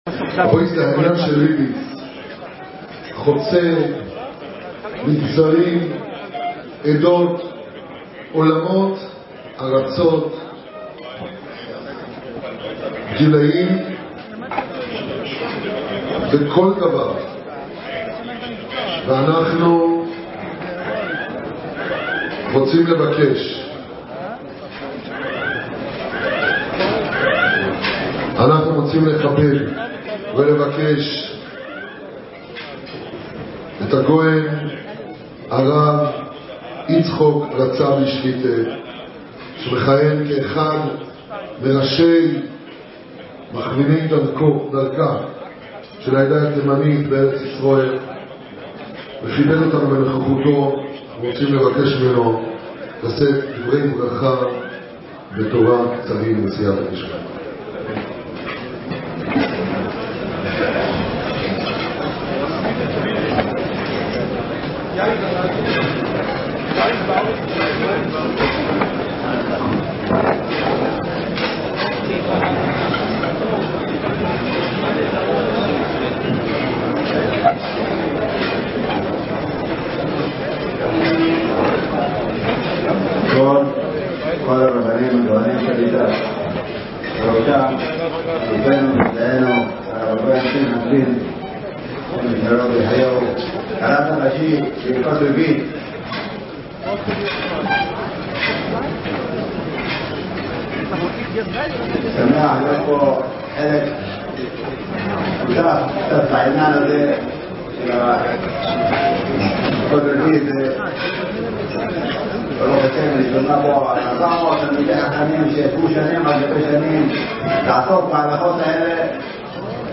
וידיאו! דברי תורה וברכה מפי מרן שליט"א - חלוקת "היתר הוראה" להלכות ריבית